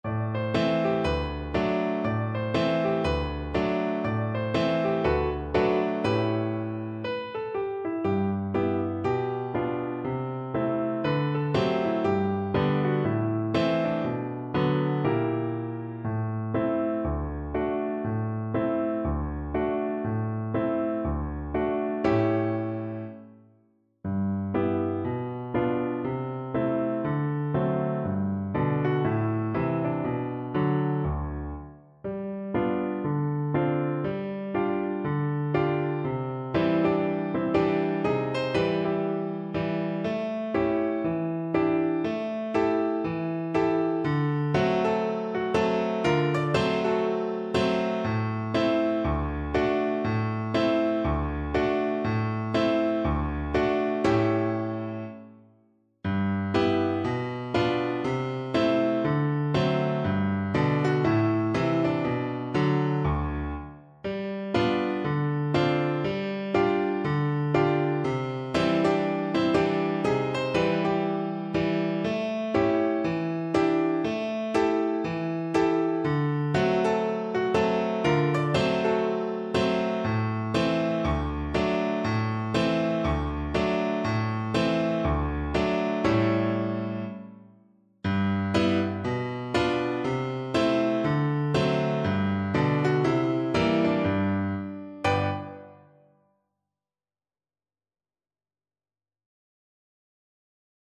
Viola version
2/2 (View more 2/2 Music)
Allegro (View more music marked Allegro)
Jazz (View more Jazz Viola Music)